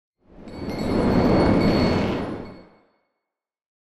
abjuration-magic-sign-rune-outro.ogg